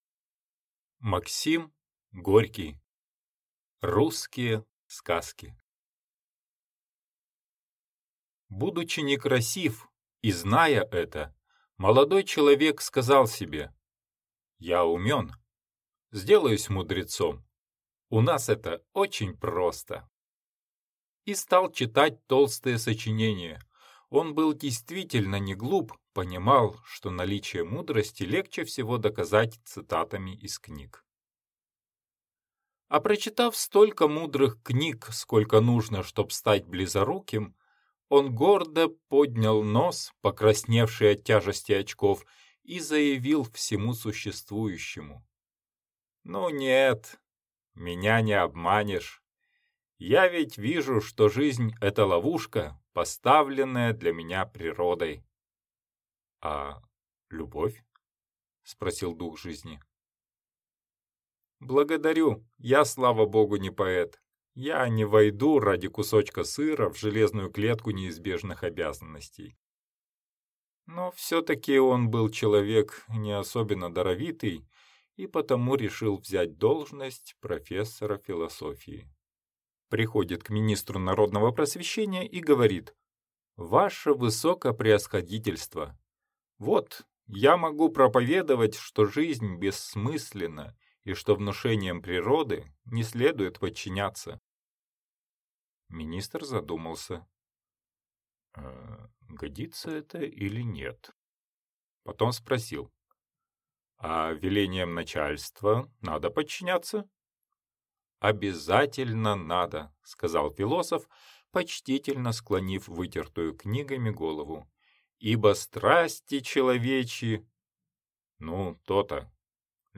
Аудиокнига Русские сказки | Библиотека аудиокниг